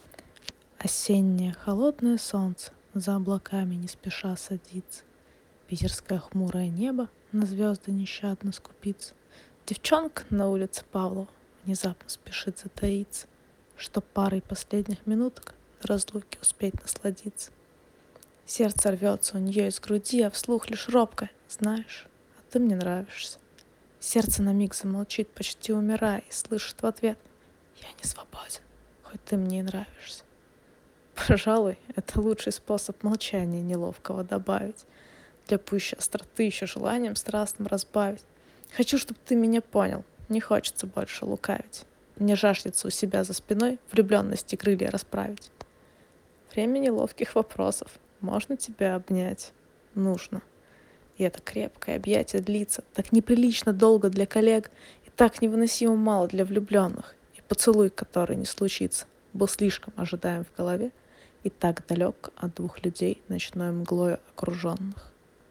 Мое собственное стихотворение, которое было написано на эмоциях от влюбленности (рекомендую слушать аудиоверсию, которая добавлена ниже, так как в ней расставлены акценты)